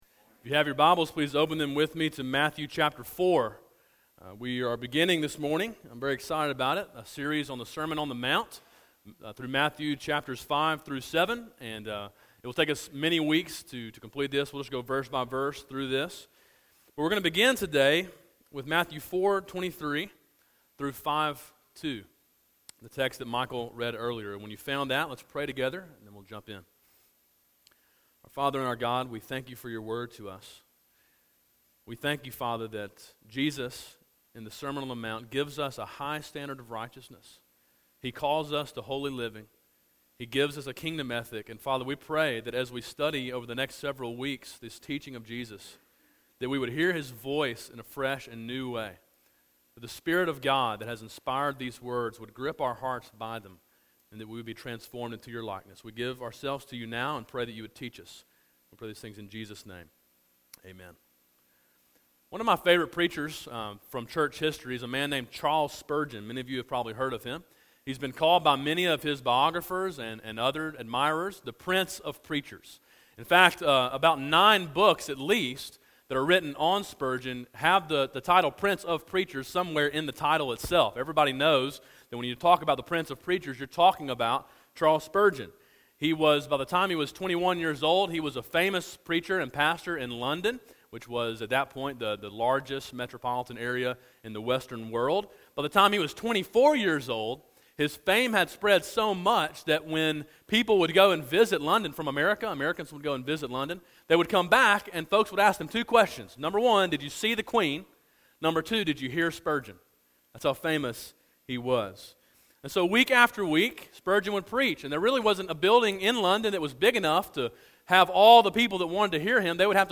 Sermon Audio: “Gospel Obedience” (Matthew 4:23-5:2)